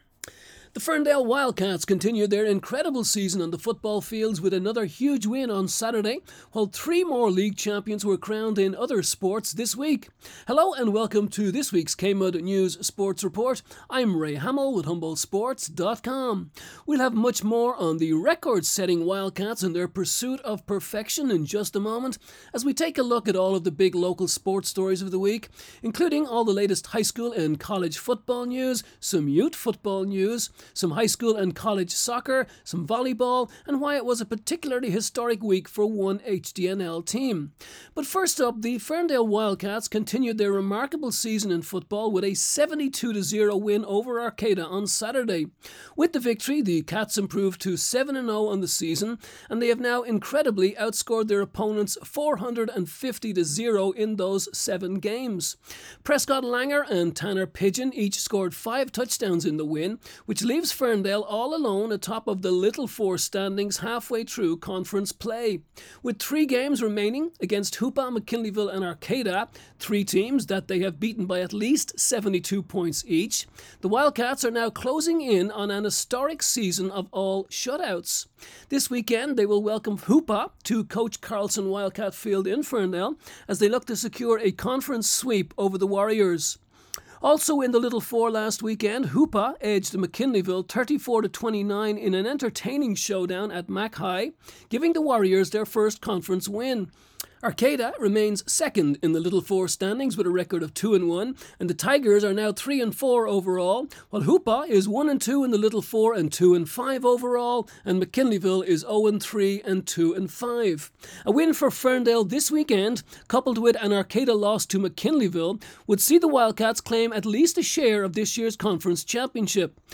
KMUD News Sports Report Oct 23